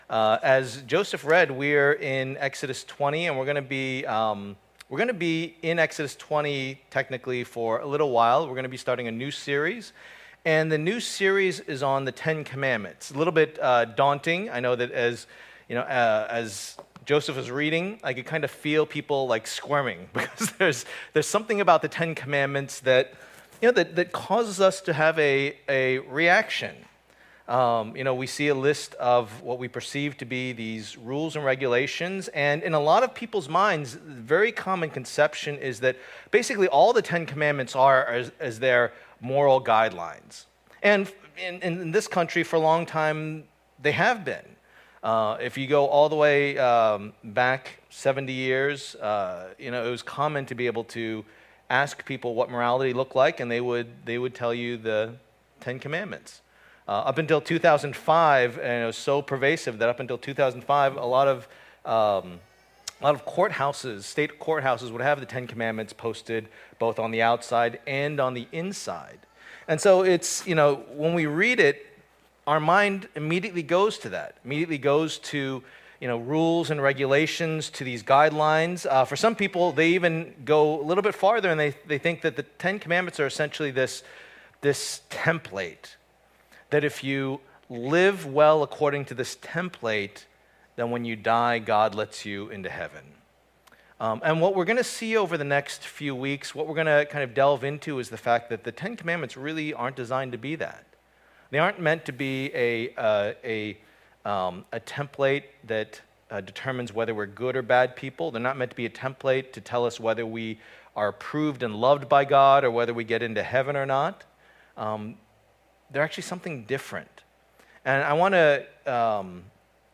The Ten Commandments Passage: Exodus 20:1-17 Service Type: Lord's Day %todo_render% « What Is It All About?